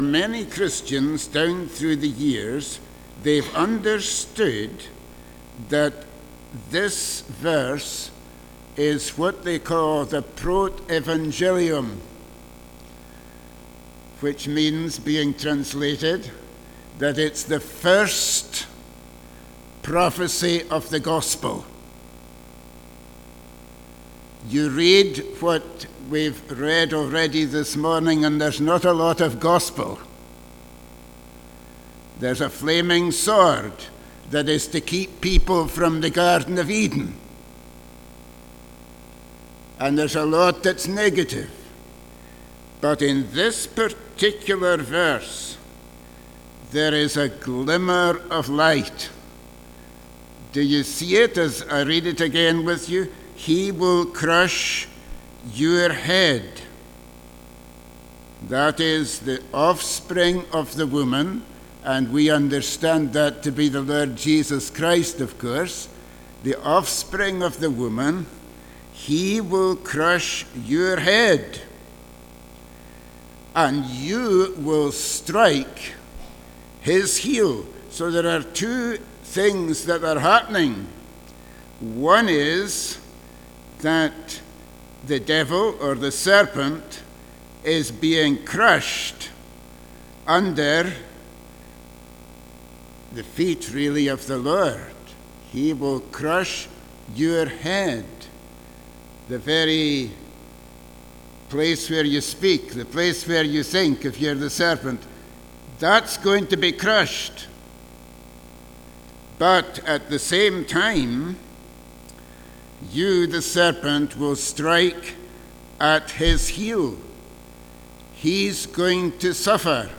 Passage: Genesis 3:14-24 Service Type: Sunday Morning « What Jesus has done for us